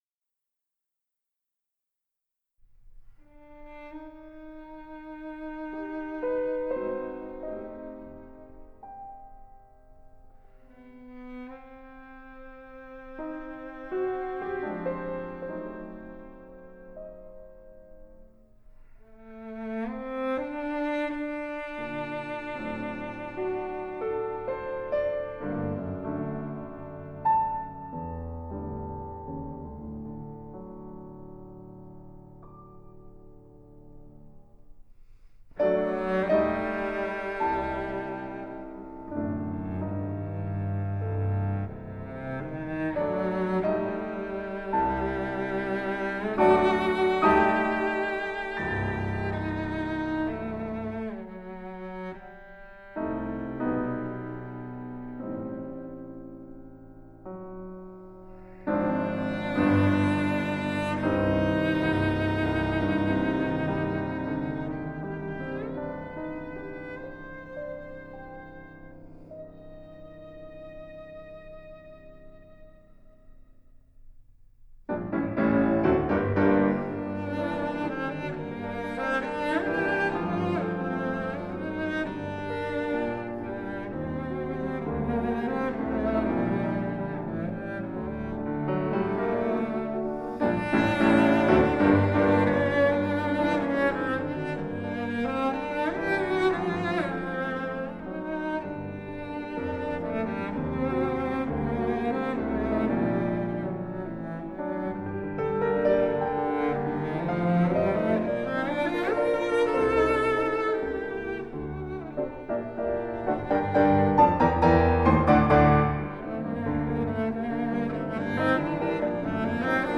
★ 於加拿大魁北克Domaine Forget音樂廳錄製！